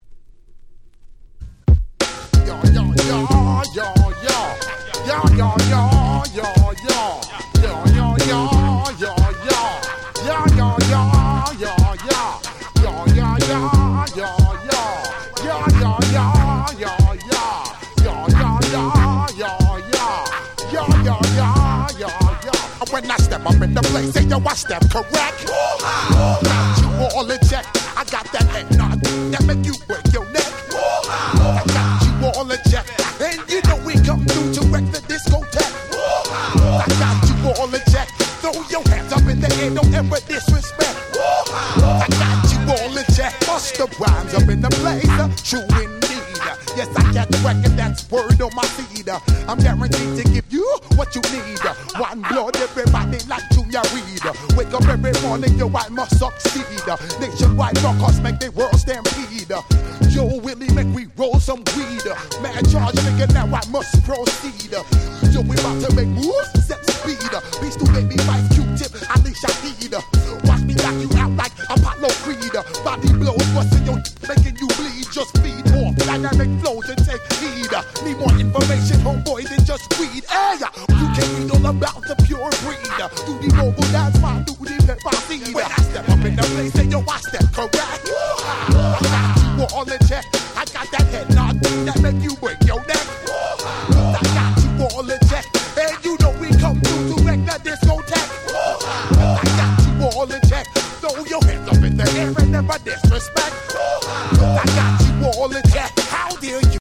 96’ Super Hit Hip Hop !!